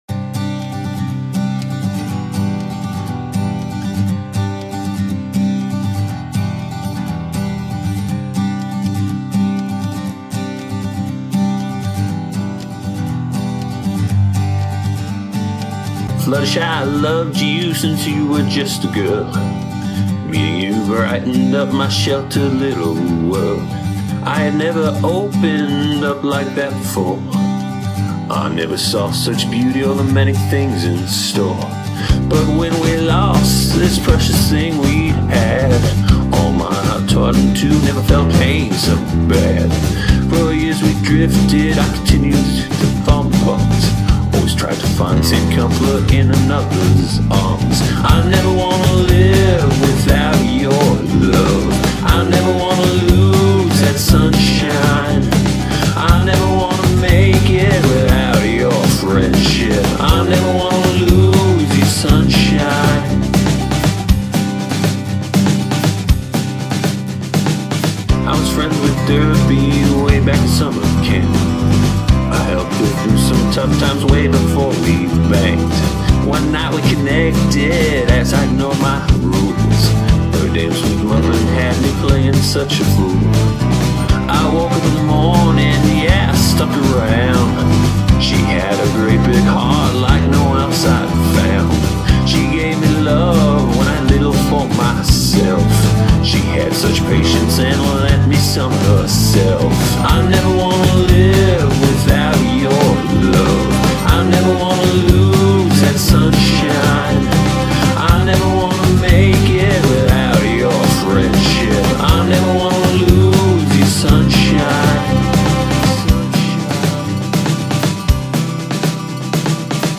So I'm accepting that while there might be some flaws apparent (not the greatest mic and a few lyrics i would change), that I can't turn back time.